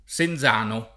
Senzano [SenZ#no] top. — freq. in diverse regioni, spesso con varianti di forma: un S. presso Parma (E.-R.) oggi mutato in Sinzano (cfr. San Martino); un S. tra la Greve e la Pesa (Tosc.) pronunziato con -z- sonora [